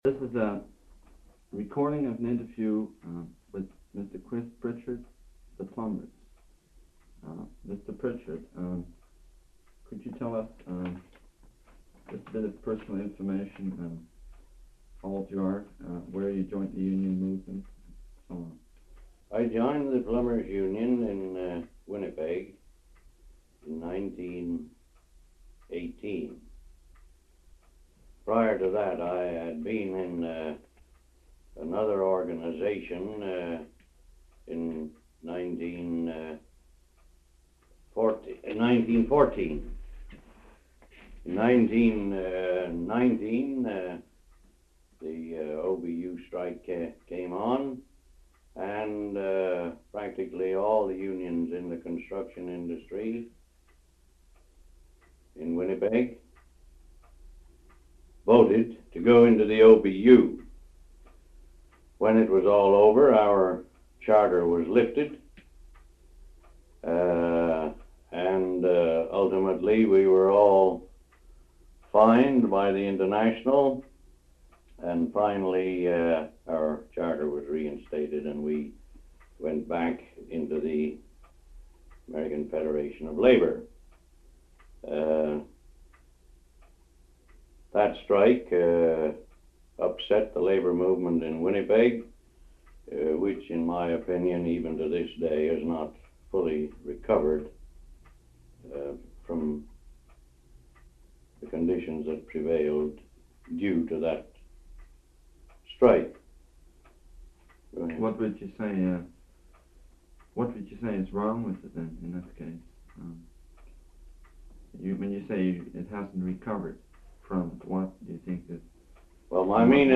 This interview provides an overview of the early history and activities of the Plumbers’ Union Local 170 in British Columbia.